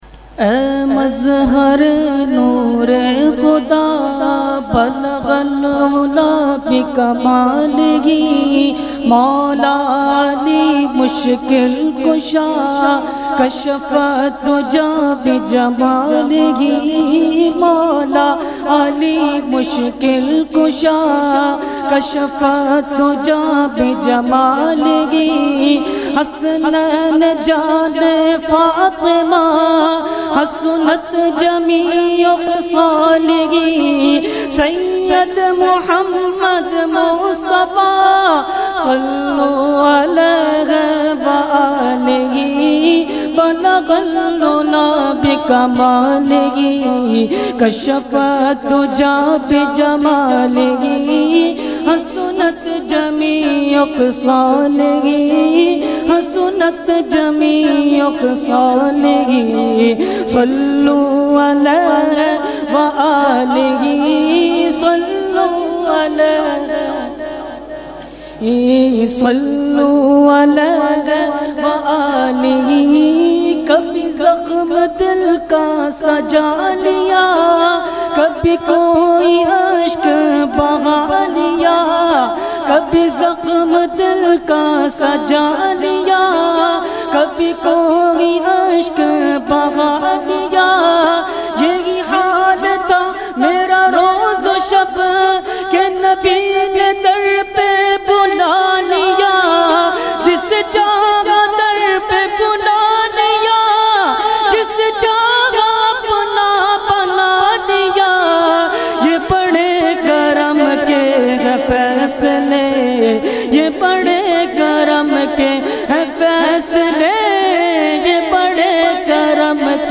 Category : Naat | Language : UrduEvent : Urs Ashraful Mashaikh 2020